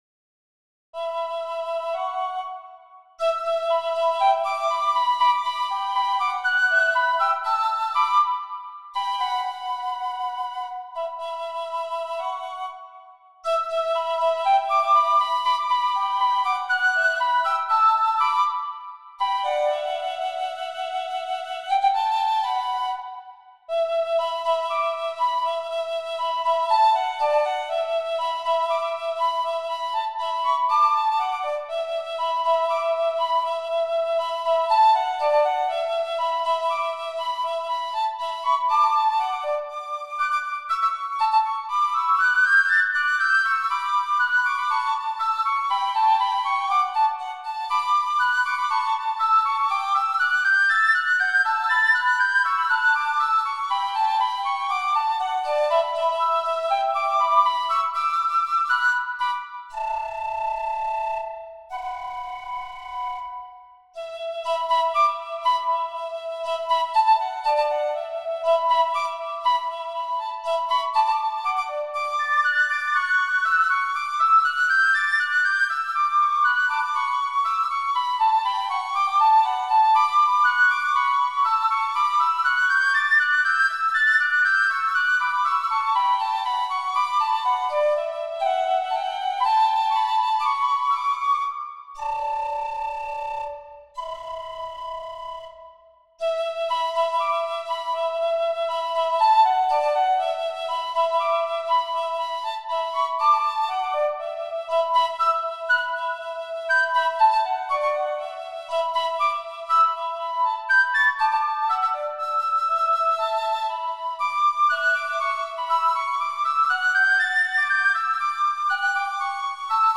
Piccolo Duos on Irish Themes
On "Drowsy Maggie" from circa the 1850s and first published in 1903 according to sources, it us an instrumental Irish reel, of somewhat obscure origins.